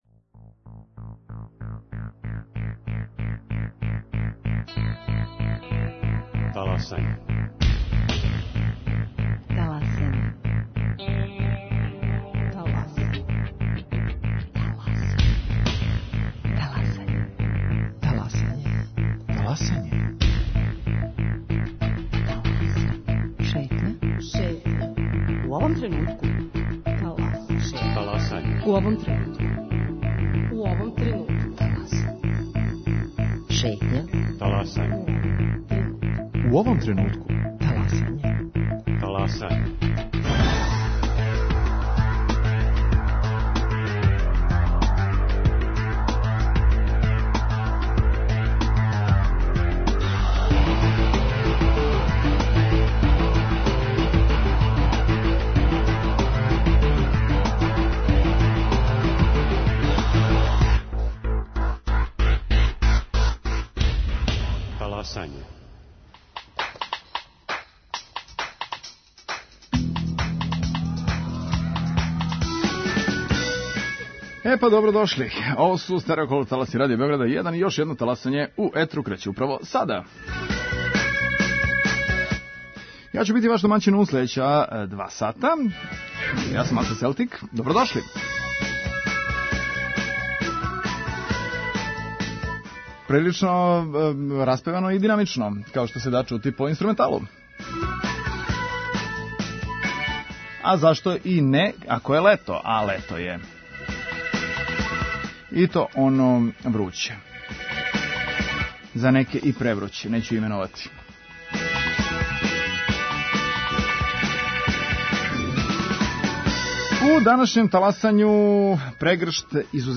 Краљевина Мароко је прославила 20. годишњицу крунисања актуелног монарха Мухамеда Шестог. Јубилеј Краљевине је обележен и у Београду, а тим поводом за Радио Београд 1 је говорио амбасадор Марока Мухамед Амин Белхаж.